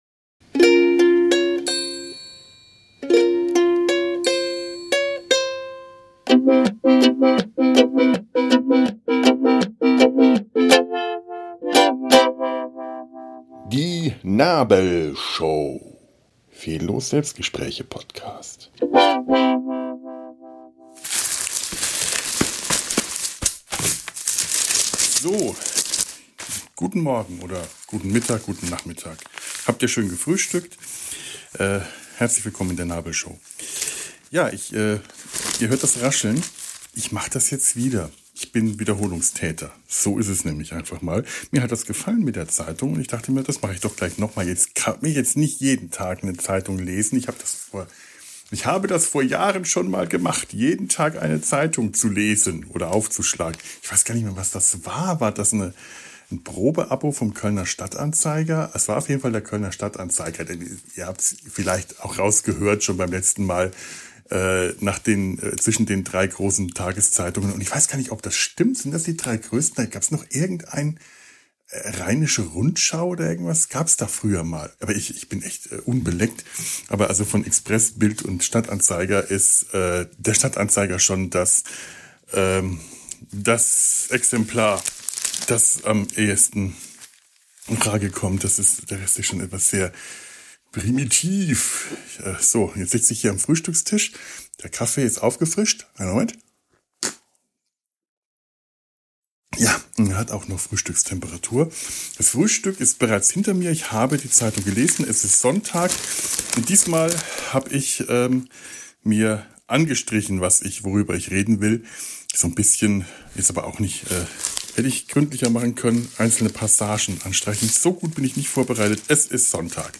Kölner Neuigkeiten aus der Wochenendzeitung: Eintrittsgeld im Dom, Alkoholverbot am Bahnhof, Bäume, Olympia und meine Heizung - - (Disclaimer: Alle vorgelesenen Zeitungs-Ausschnitte stammen aus dem Kölner Stadtanzeiger, aus der Wochenend-Ausgabe vom 11.4.2026. Die Inhalte sind in ihrer jeweiligen Lä...